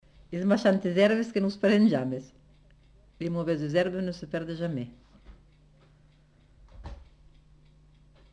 Lieu : Cathervielle
Genre : forme brève
Type de voix : voix de femme
Production du son : récité
Classification : proverbe-dicton